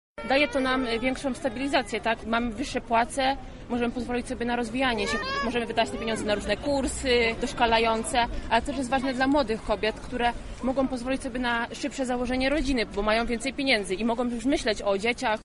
Wiec poparcia lubelskich kobiet dla Andrzeja Dudy.